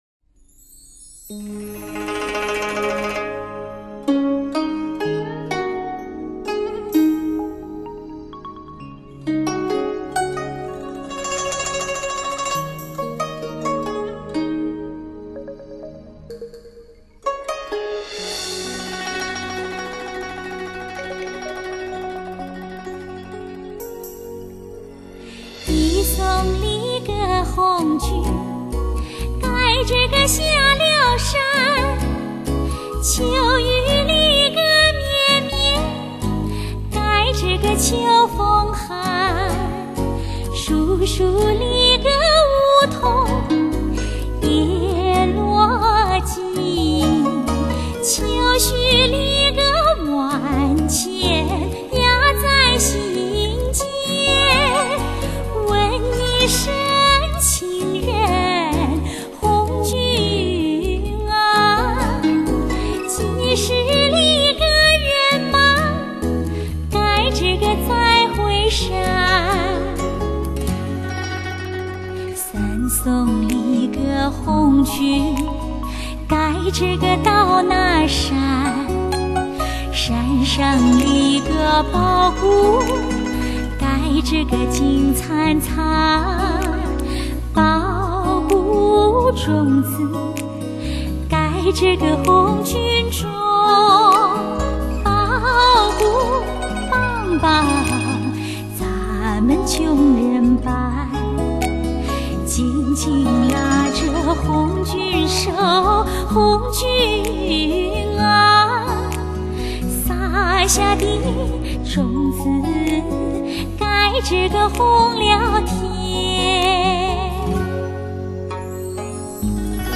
超级模拟A2HD3后期母带处理技术与超级压片HQCD制式完美结合